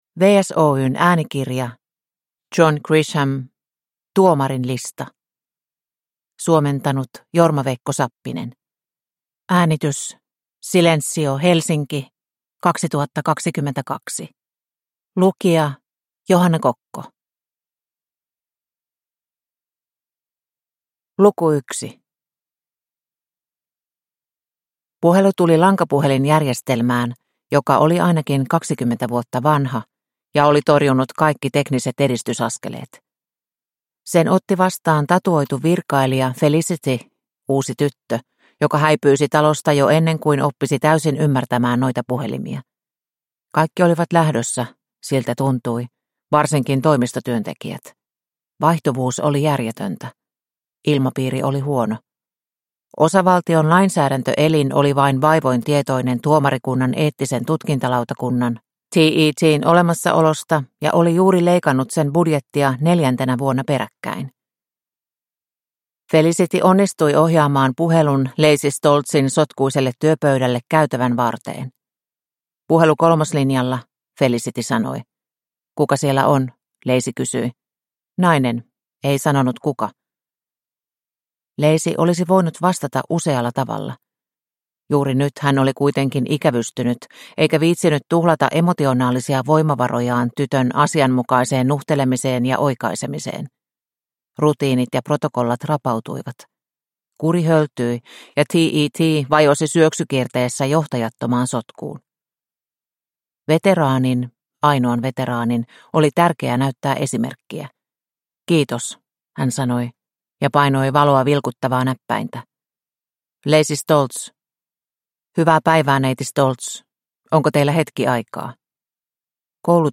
Tuomarin lista – Ljudbok – Laddas ner